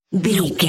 Bright Implode
Sound Effects
Atonal
magical
mystical